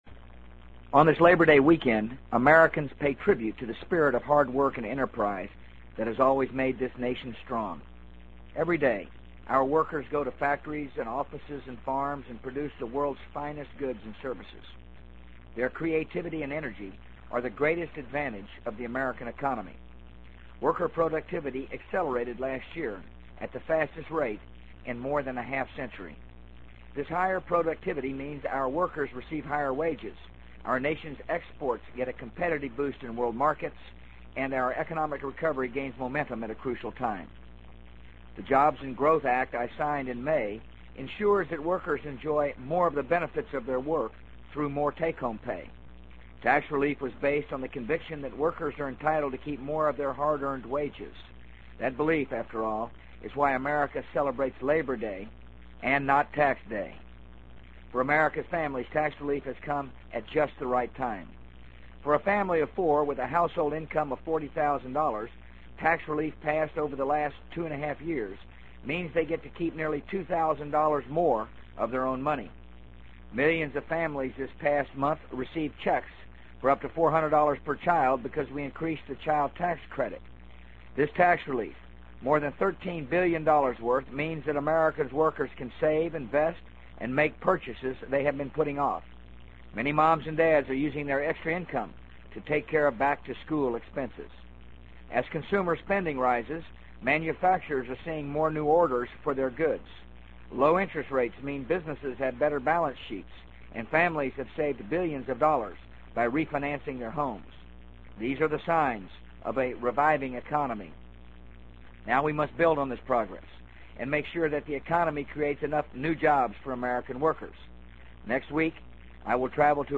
【美国总统George W. Bush电台演讲】2003-08-30 听力文件下载—在线英语听力室